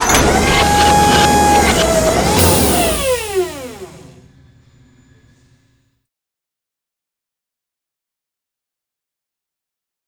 combat
retract.wav